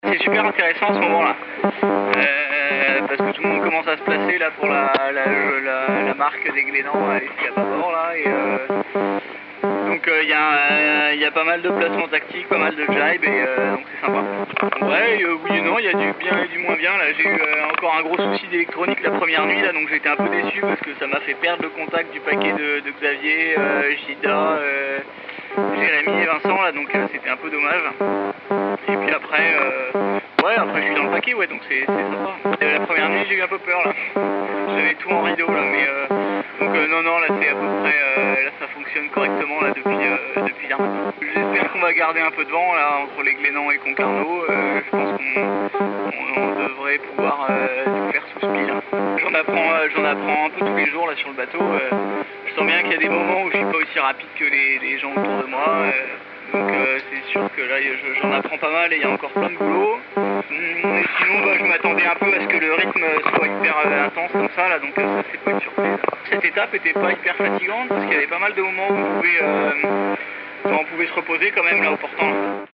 (Non il n’écoute pas de l’électro à bord, mais la liaison satellite n’est pas excellente !)